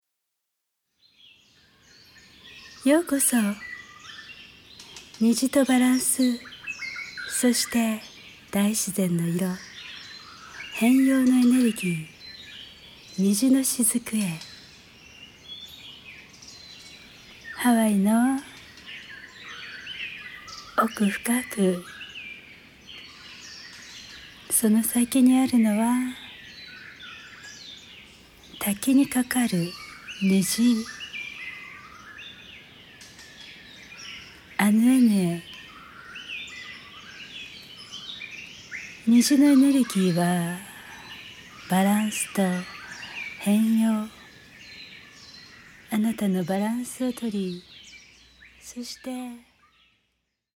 柔らかい声で「さあ、息を吐きましょう」というように潜在意識に呼び掛けてくれるので、